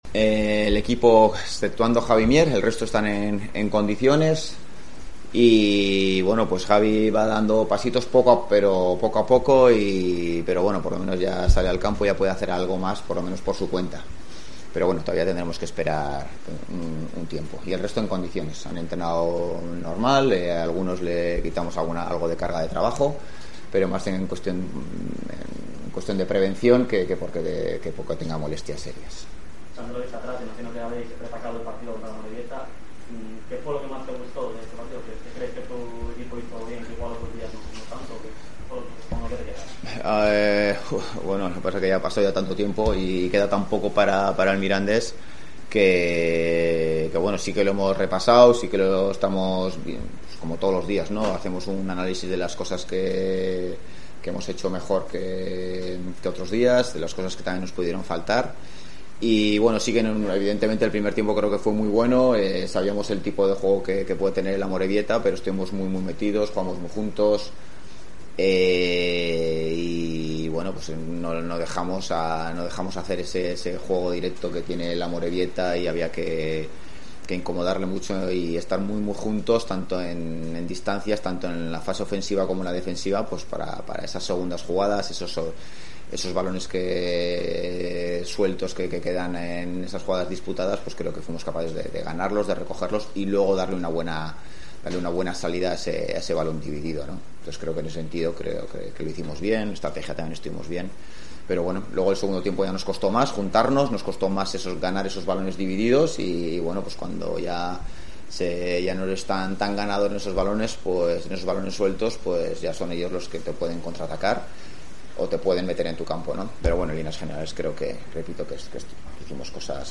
Rueda de prensa Ziganda (previa Mirandés)